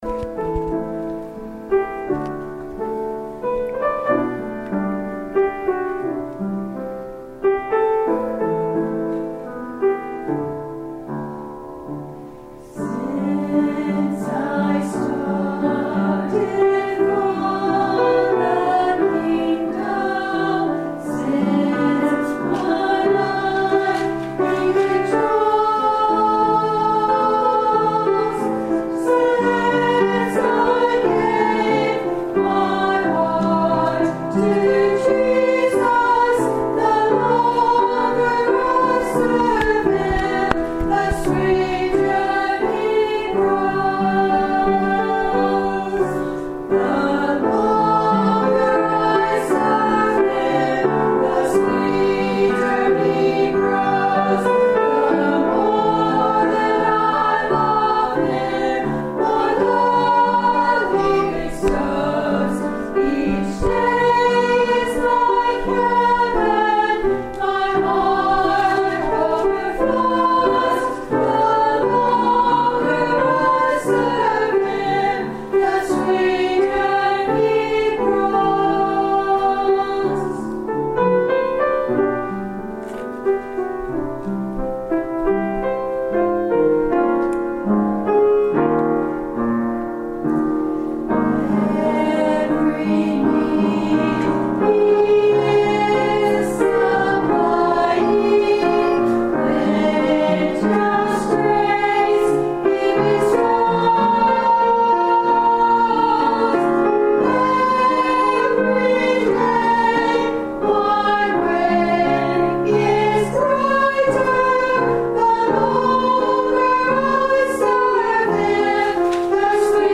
ROSINTON UMC REVIVAL OCTOBER 16-19, 2016